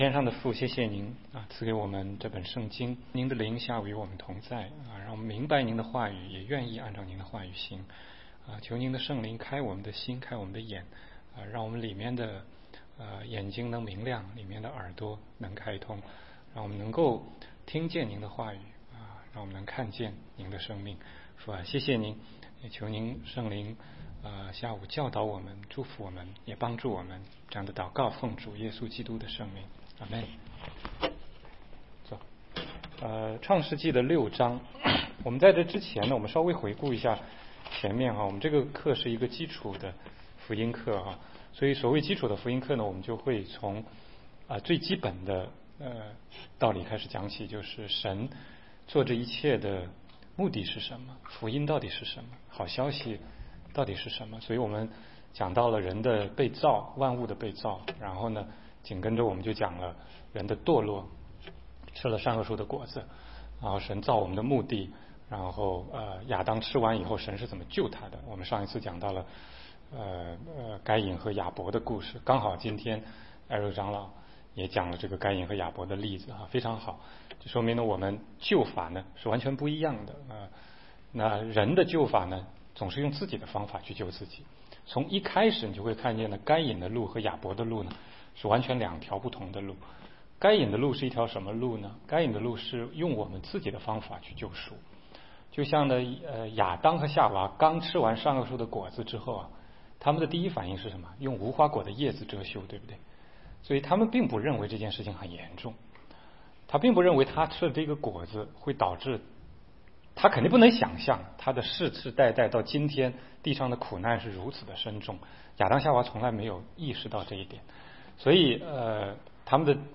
16街讲道录音 - 挪亚方舟的救赎